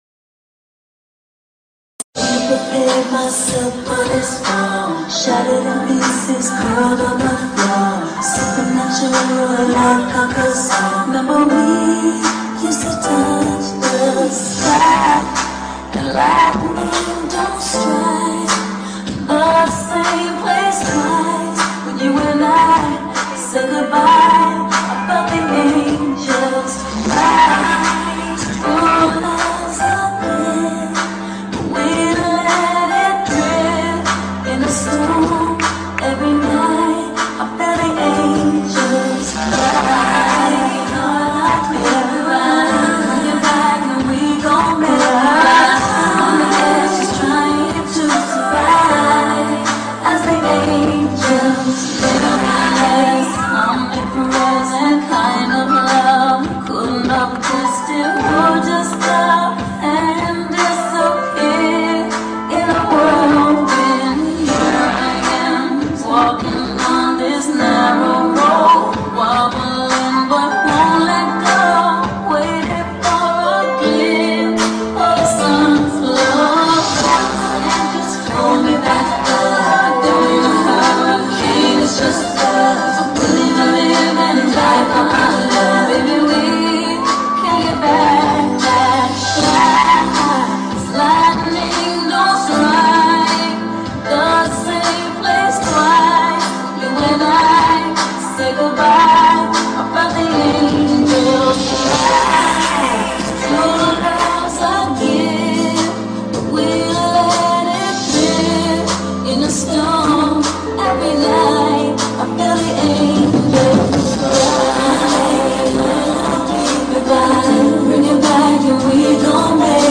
R&B